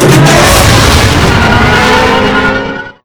speeder_boost4.wav